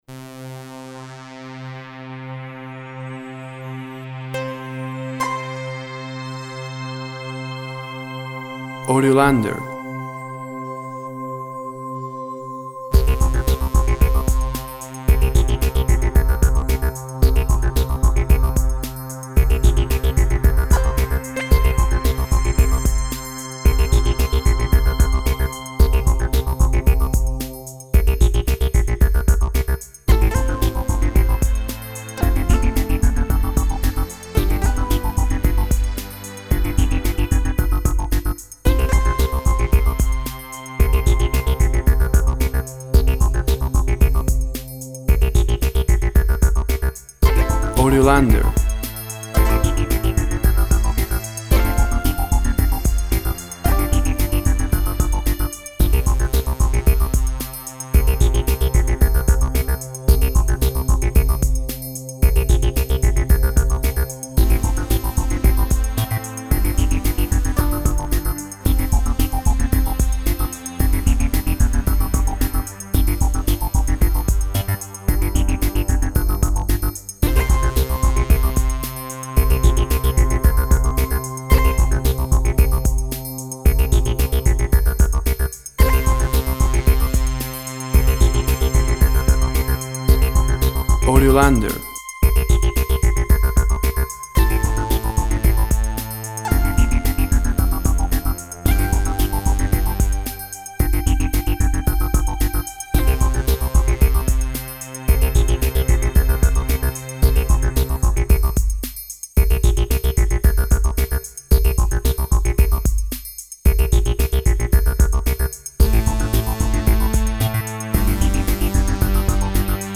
Free functional music, electronic sounds and dancebeat.
Tempo (BPM) 112